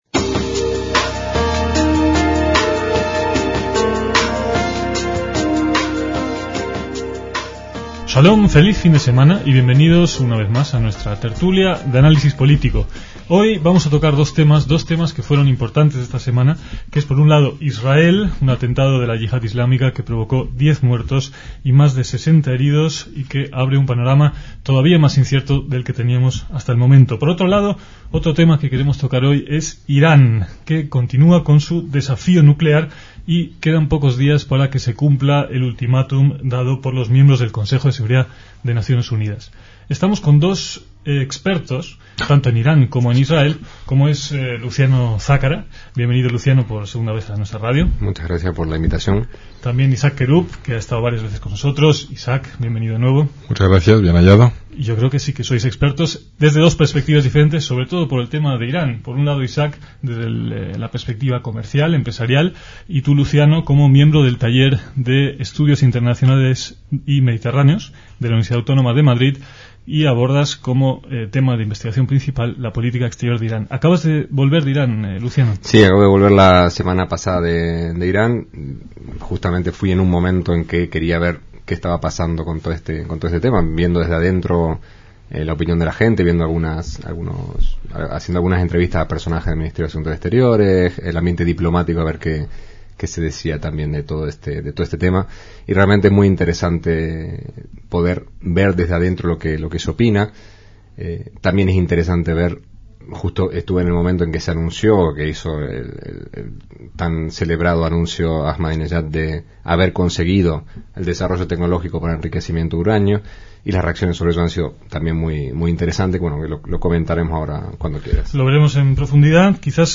DECÍAMOS AYER (22/4/2006) - Irán, su desarrollo nuclear y el ultimatum del Consejo de Seguridad de Naciones Unidas (en 2006) monopolizaron en aquella ocasión del debate de los contertulios.